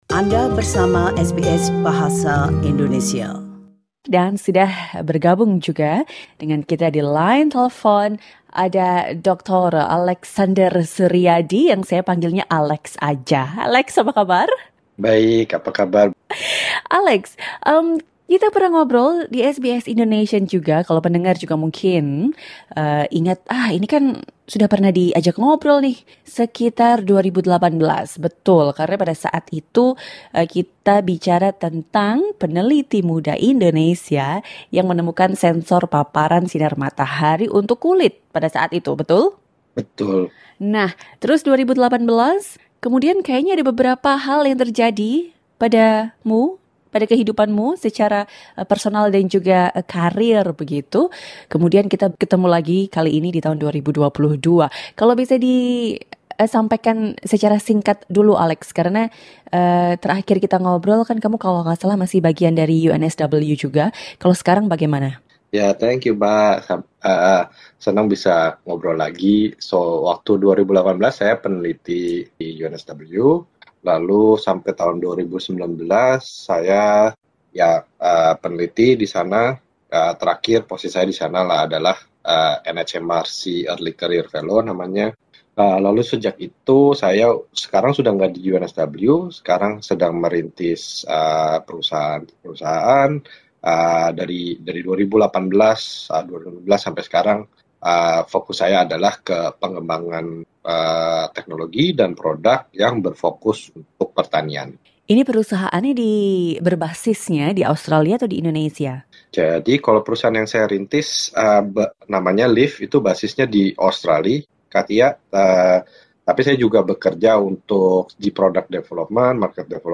wawancara SBS Indonesian